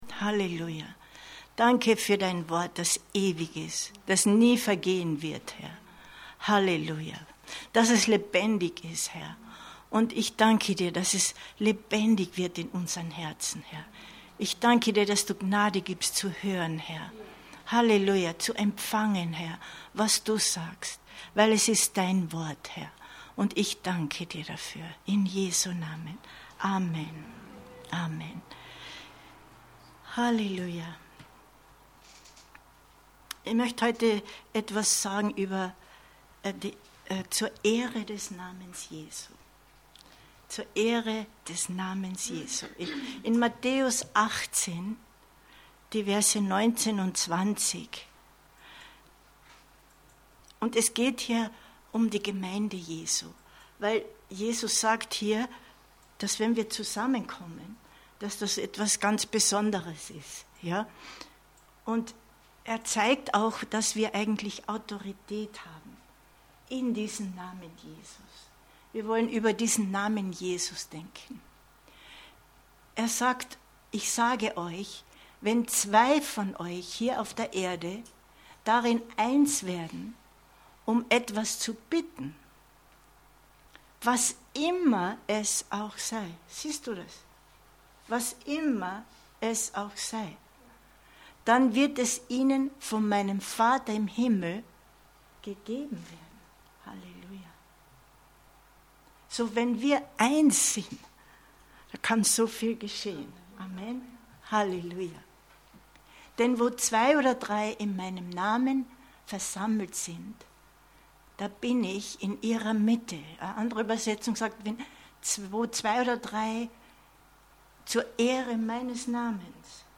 Info Info Zur Ehre des Namens Jesu 21.08.2022 Predigt herunterladen